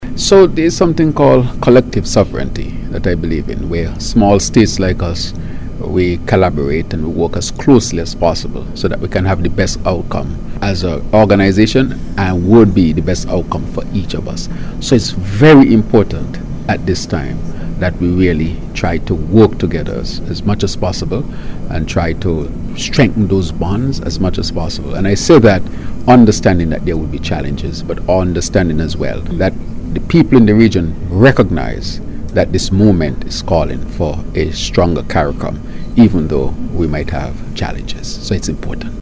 He made this statement at the Argyle International Airport Tuesday, during an official visit to St. Vincent and the Grenadines.